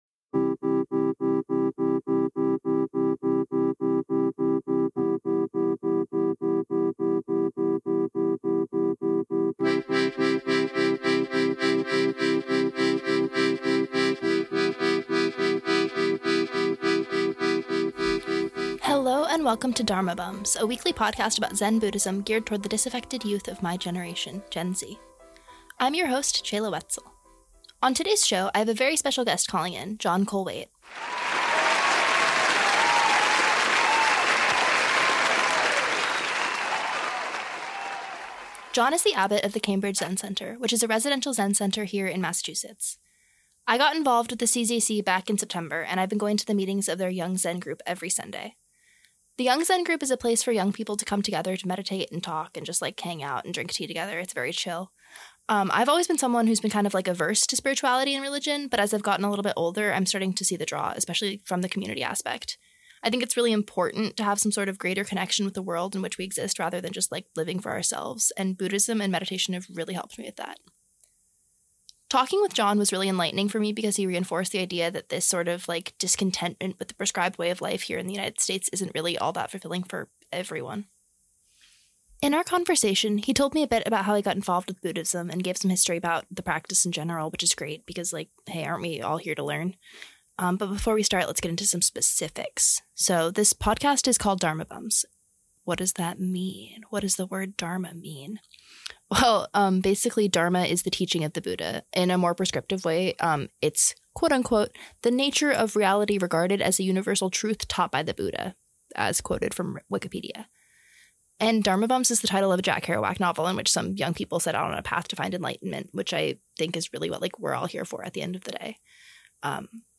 0:00- Intro Music
2:20- Guest call
14:18- Guided meditation (franchise element)